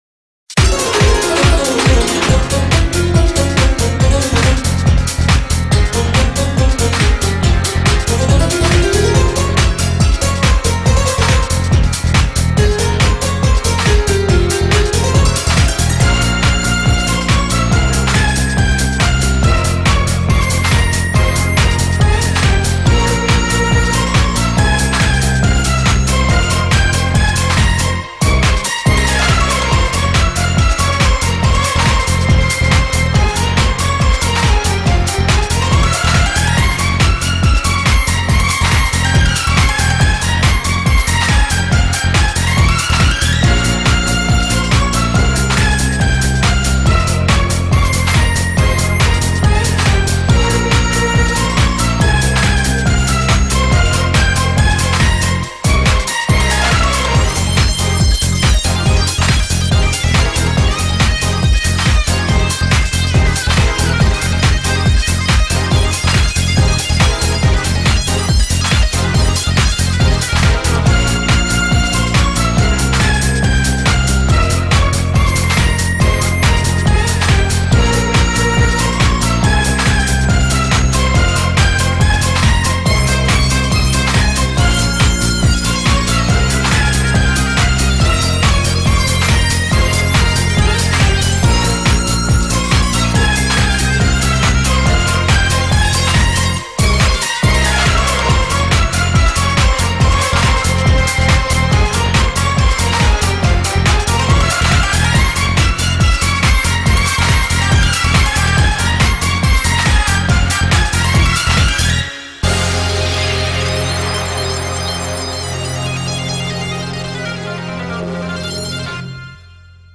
BPM140
Audio QualityCut From Video